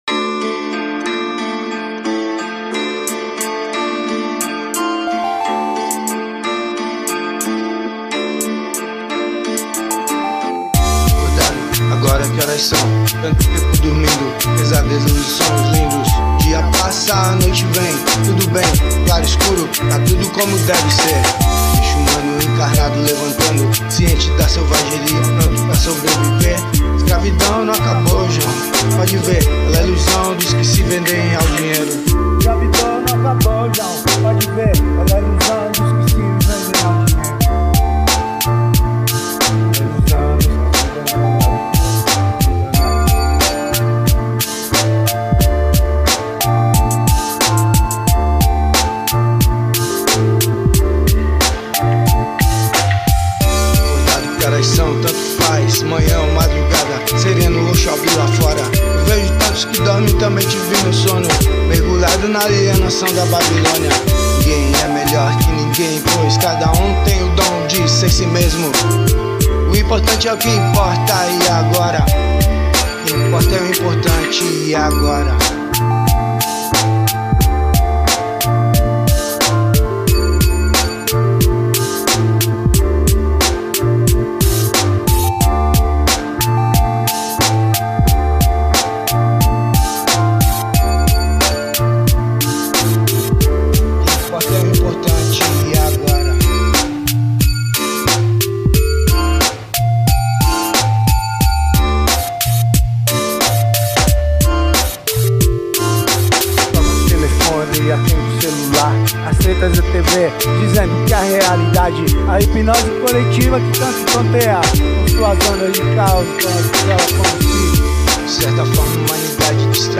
Instrumental eletronico manual